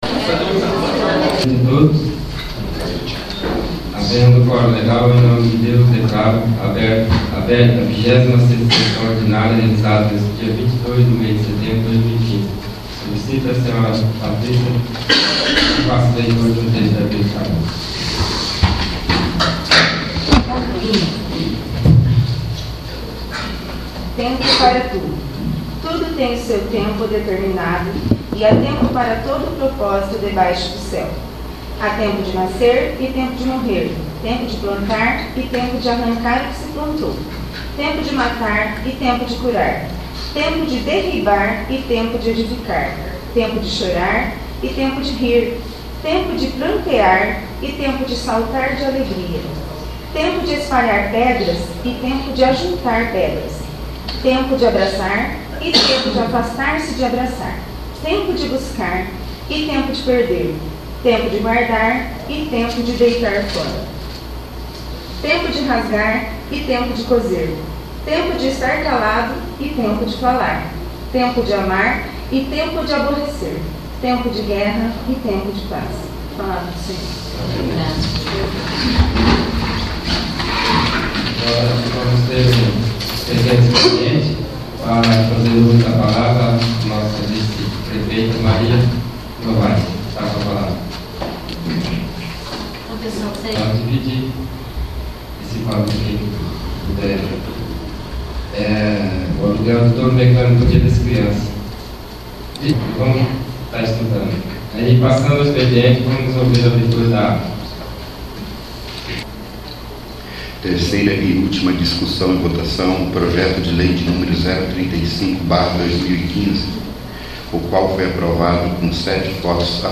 26º. Sessão Ordinária